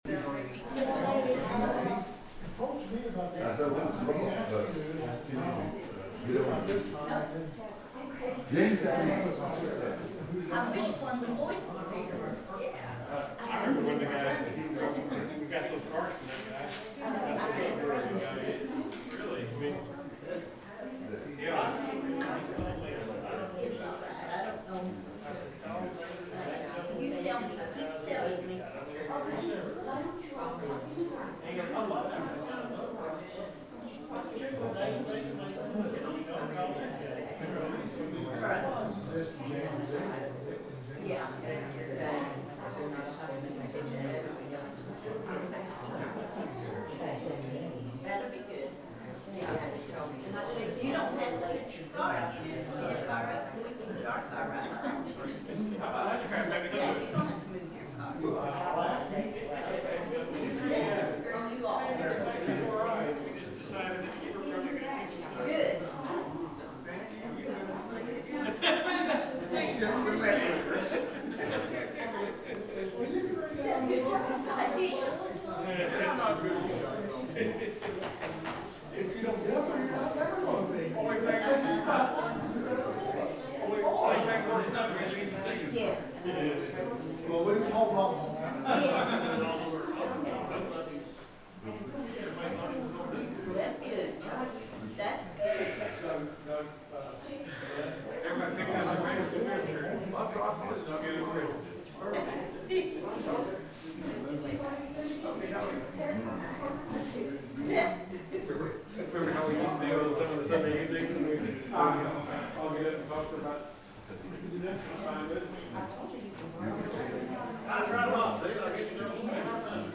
The sermon is from our live stream on 12/10/25